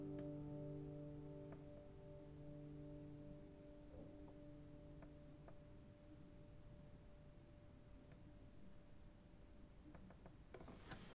This ending section where the last note dies off is a classic place to hear self-noise generated by mics and preamps, along with the room noise (and the belly noise).
I also made a second set of clips with just the tails, and here I raised the gain significantly.
Zoom H2 Tail
And the H2n does improve on the noise performance of the H2, but it’s still noticeably noisier than the Rode & Echo combination.